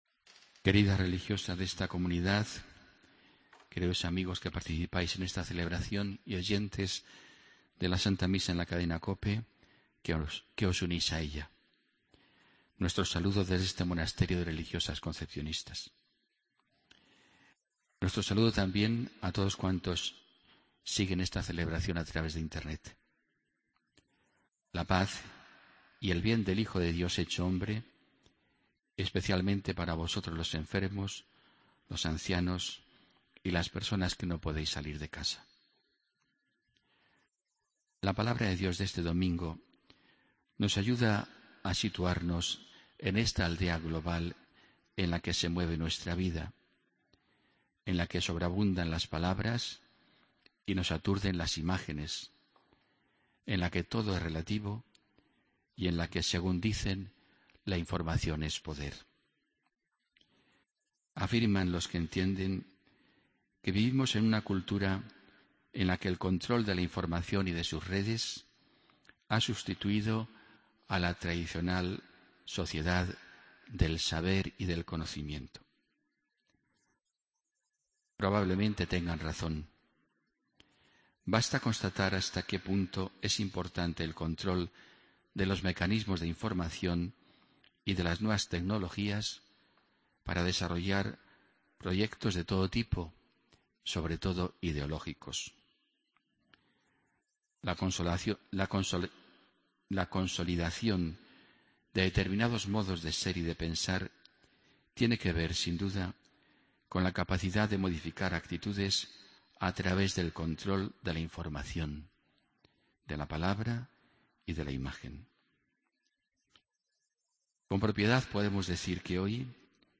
Homilía del domingo 3 de enero de 2016